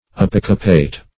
Apocopate \A*poc"o*pate\, v. t. [LL. apocopatus, p. p. of